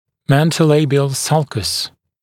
[ˌmentəu’leɪbɪəl ˈsʌlkəs][ˌмэнтоу’лэйбиэл ˈсалкэс]подбородочная борозда